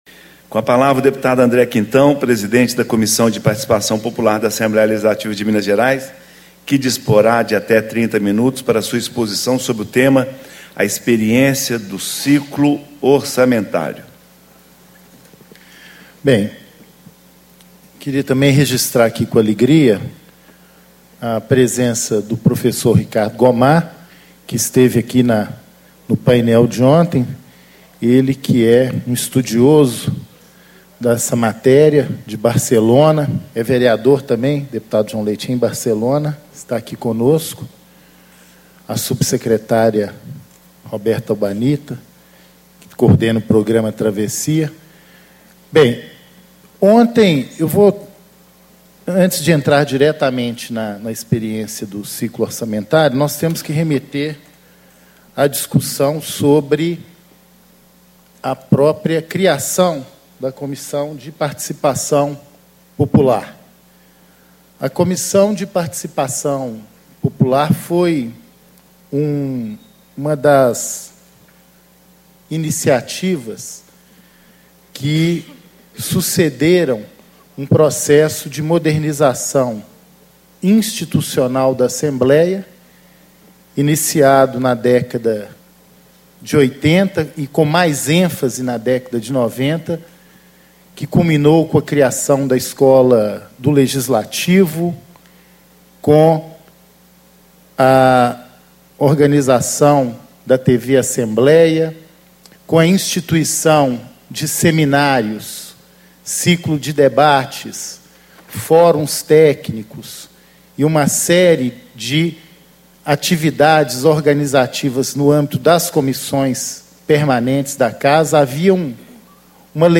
Palestra: Deputado André Quintão, PT - Presidente da Comissão de Participação Popular - Painel A experiência do Ciclo Orçamentário